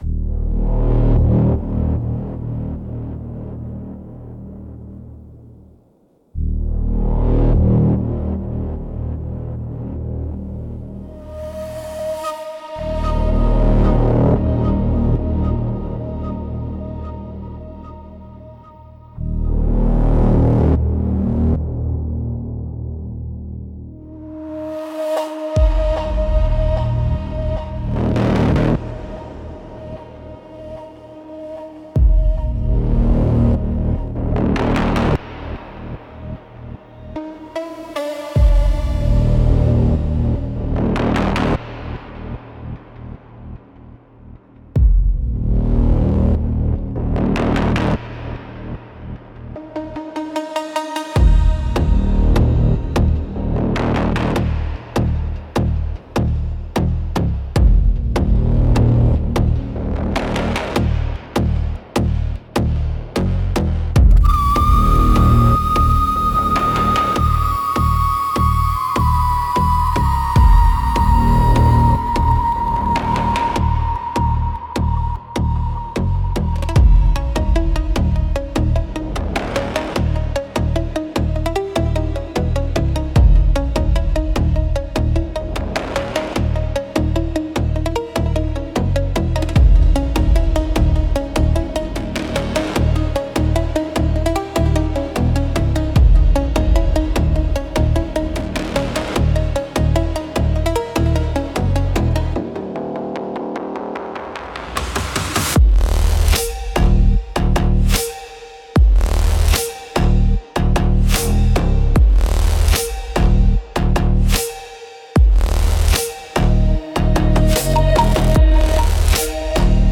Instrumentals - A Dirge for Dead Circuits